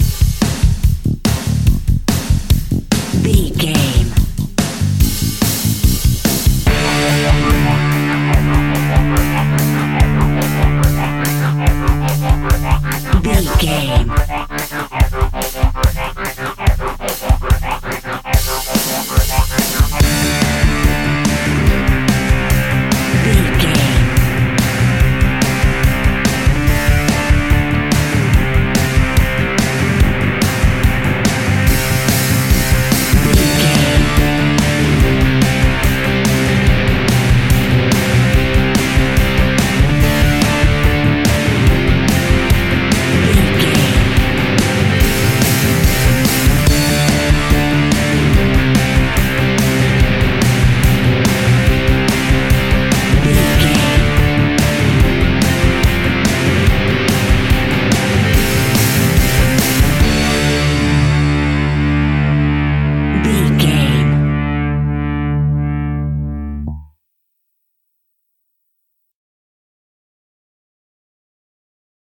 Epic / Action
Fast paced
Mixolydian
hard rock
blues rock
rock instrumentals
Rock Bass
heavy drums
distorted guitars
hammond organ